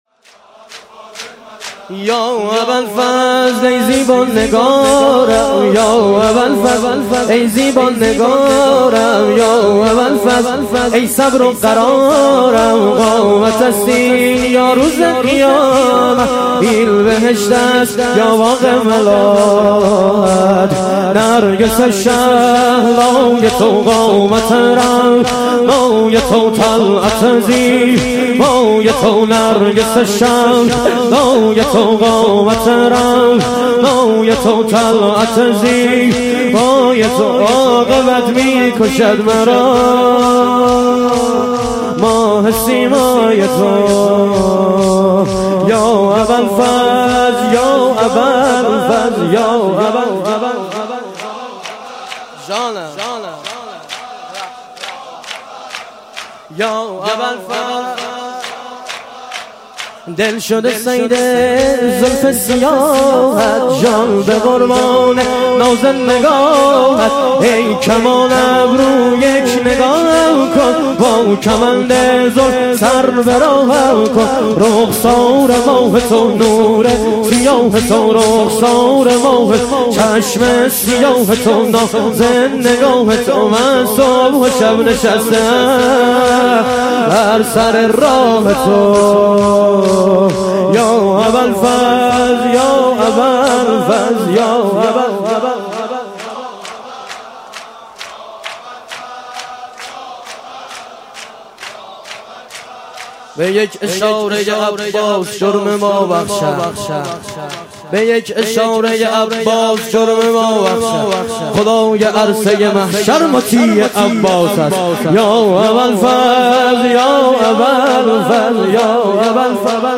شهادت امام حسن عسکری علیه السلام - شور - 5 - 1397